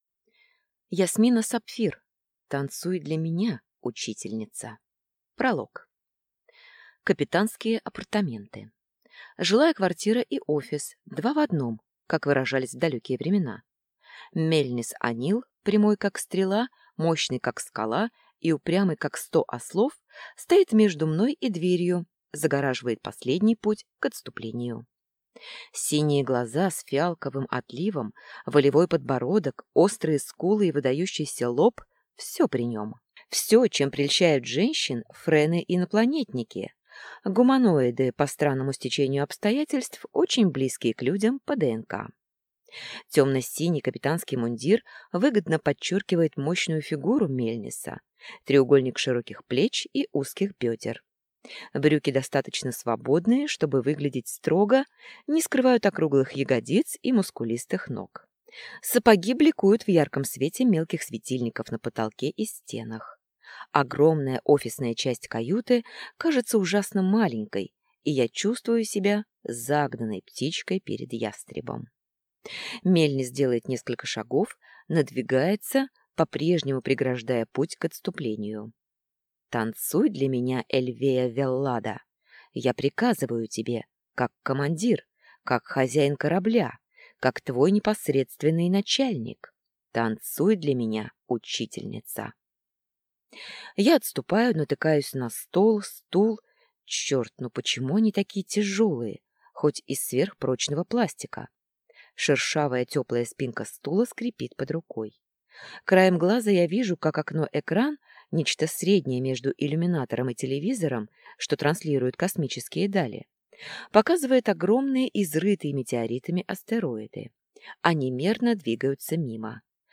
Аудиокнига Танцуй для меня, учительница!